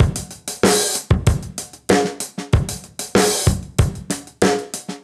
Index of /musicradar/sampled-funk-soul-samples/95bpm/Beats
SSF_DrumsProc1_95-04.wav